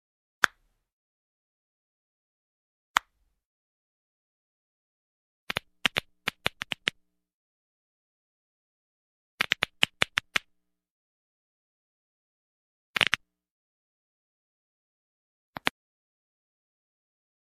Звуки пальцев
Звук щелчка пальцами и хруст суставами